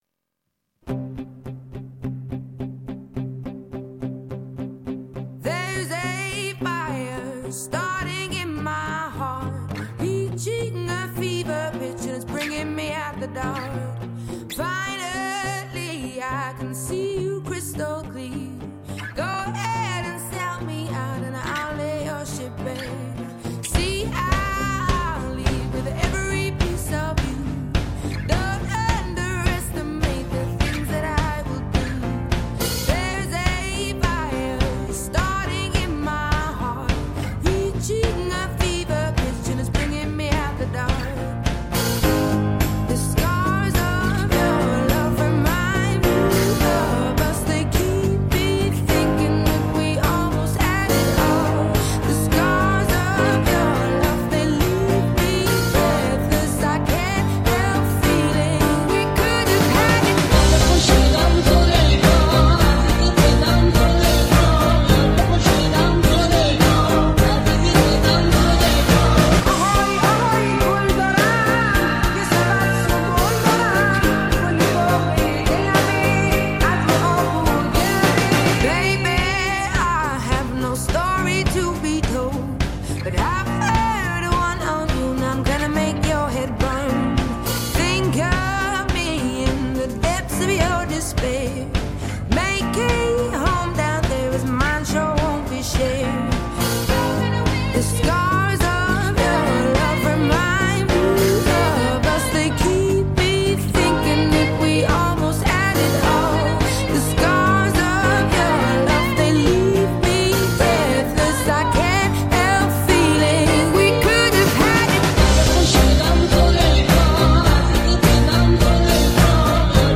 دانلود اهنگ پاپ